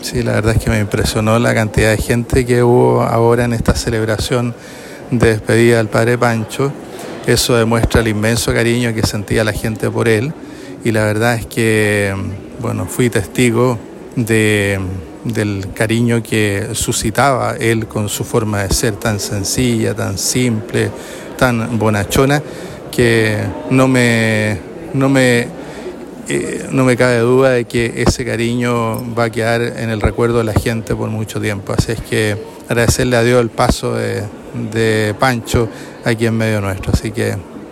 Tras la ceremonia, el obispo de Osorno, Carlos Godoy Labraña, destacó la profunda entrega del sacerdote hacia la comunidad, reflejada en la gran cantidad de personas que participaron tanto en la misa como en el último adiós en el Cementerio Católico.